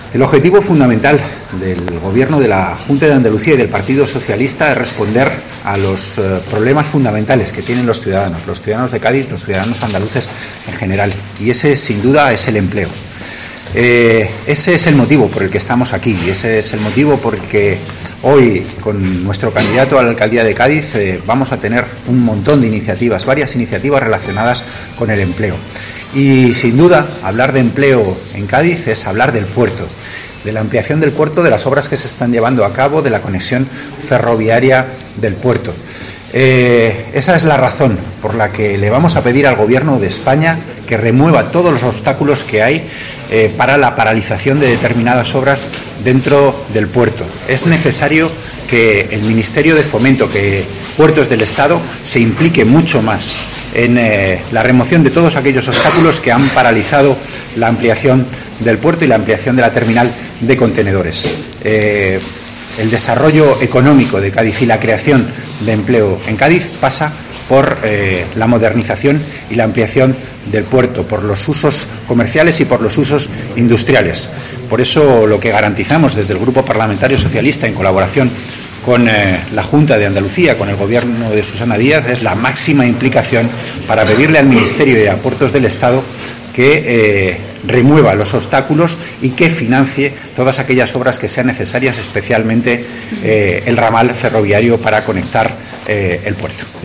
Fragmento de la rueda de prensa de Antonio Hernando durante su visita a Cádiz 16/1/2015